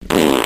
Sound Effects
Loud Fart